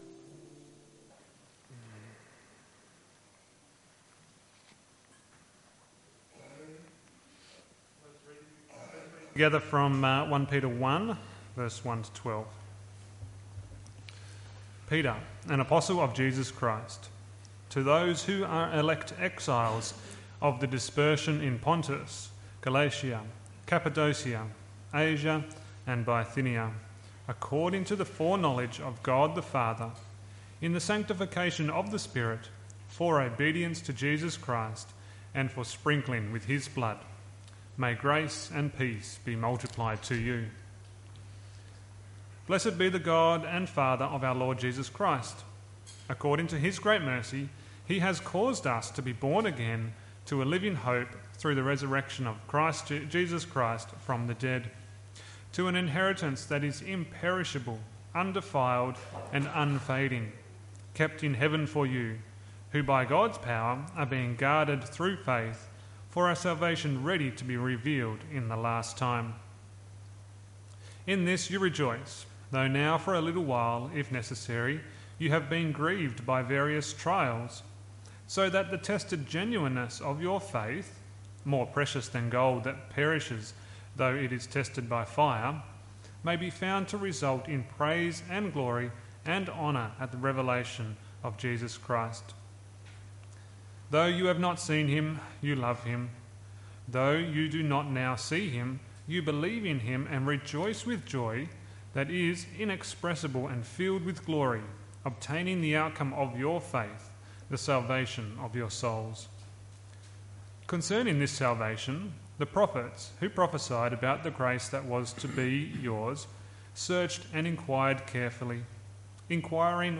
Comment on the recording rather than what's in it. MP3 SUBSCRIBE on iTunes(Podcast) Notes 16 April 2023 Morning Service 1 Peter 1:1-12 John 3:1-10 A person must be born again Why? A person is born again by God Himself